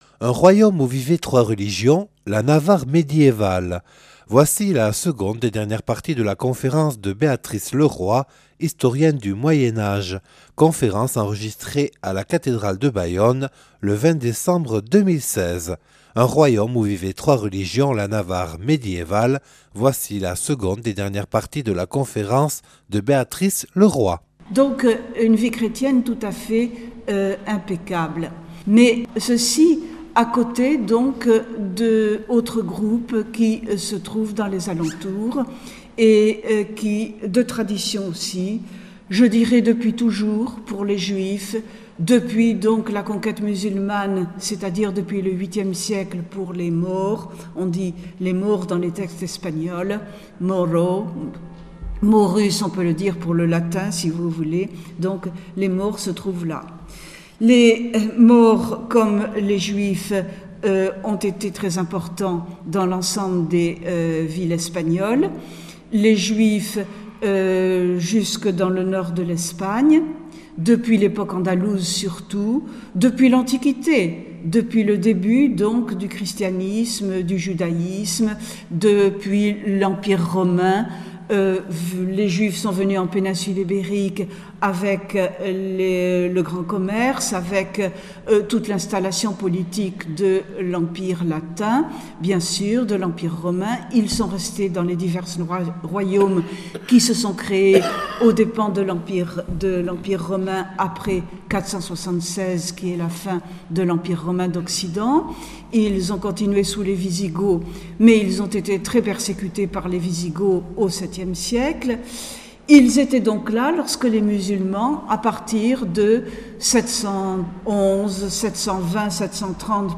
(Enregistré le 26/12/2016 à la cathédrale de Bayonne).